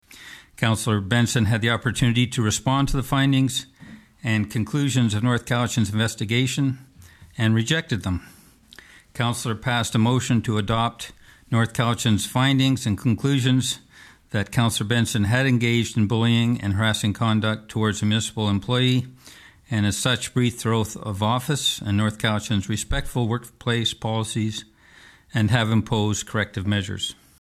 Just what measures have been imposed on Councillor Joyce Benson has not been released, but Mayor Jon Lefebure read a statement about the incident that led to the decision ahead of Monday’s Special Council Meeting.